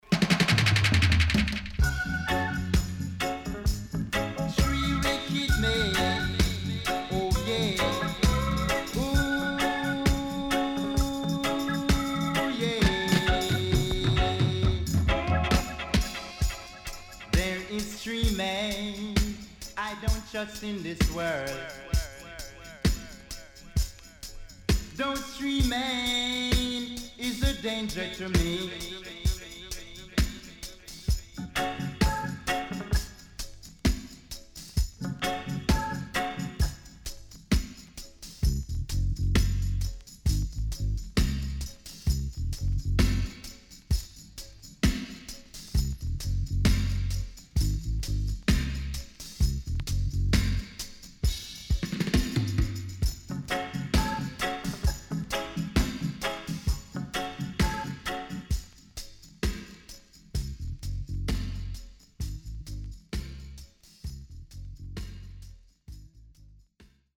Killer Deep Roots Vocal & Dubwise.Good Condition
SIDE A:少しチリノイズ入りますが良好です。